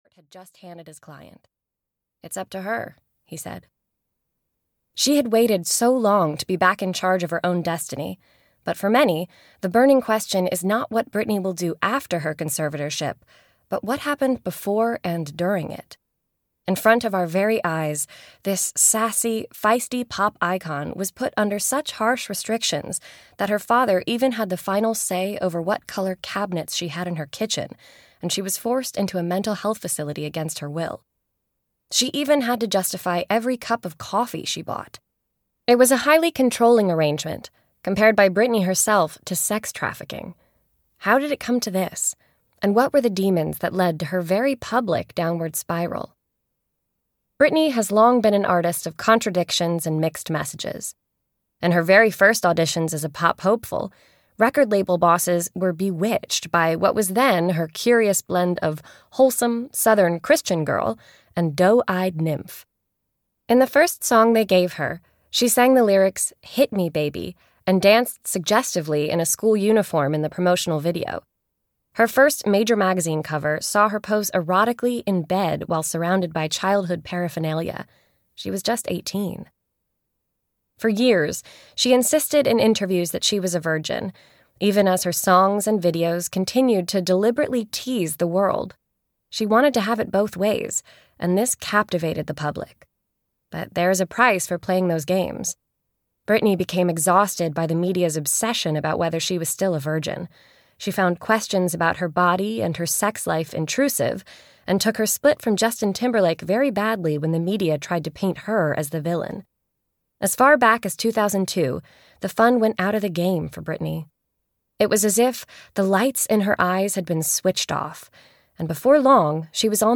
Audiobook BRITNEY: Breaking Free, written by Danny White.
Ukázka z knihy